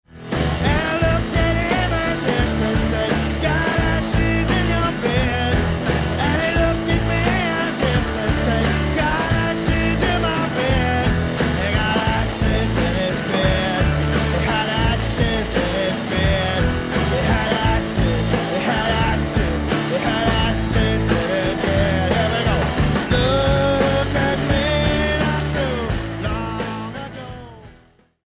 bass and tuba
drums and percussion
piano and vocals
electric guitar
mandolin
accordian and electric guitar